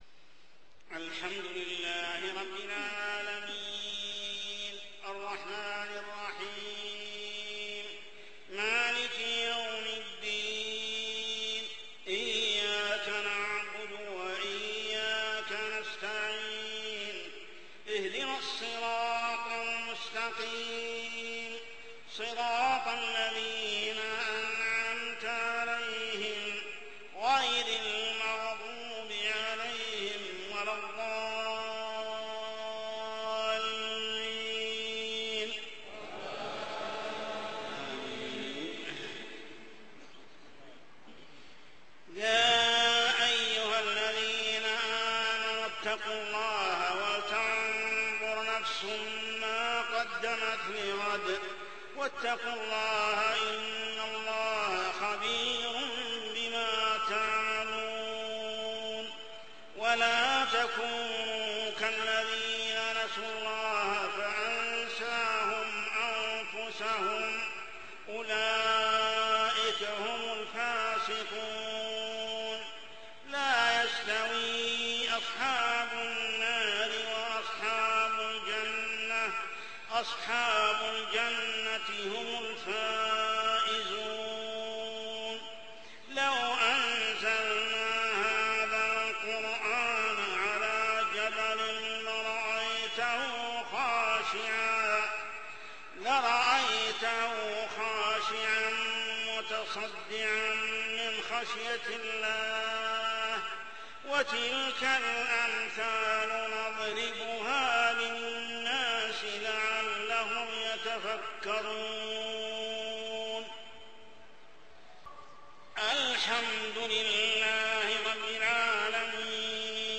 صلاة العشاء عام 1428هـ سورة الحشر 18-24 | Isha prayer Surah Al-hashr > 1428 🕋 > الفروض - تلاوات الحرمين